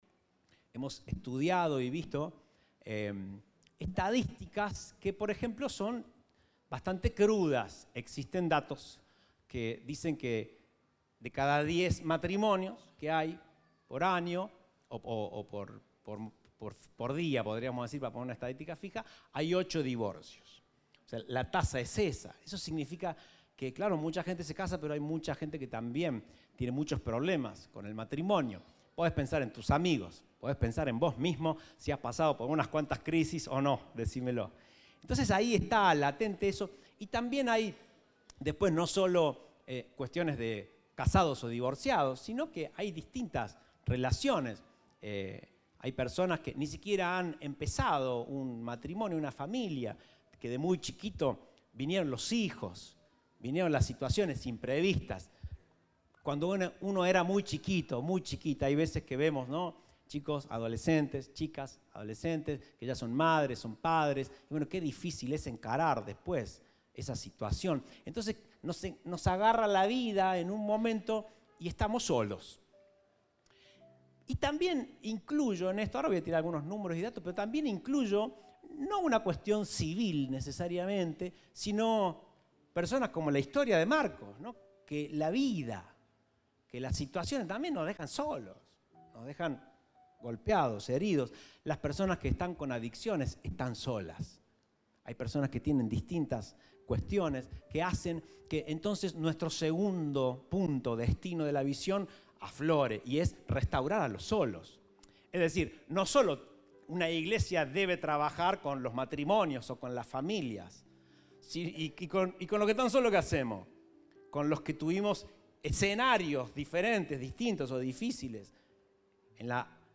Compartimos el mensaje del Domingo 8 de Octubre de 2023